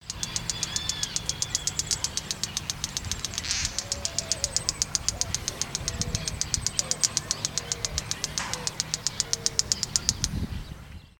Bate-bico (Phleocryptes melanops)
Nome em Inglês: Wren-like Rushbird
Detalhada localização: Laguna
Condição: Selvagem
Certeza: Fotografado, Gravado Vocal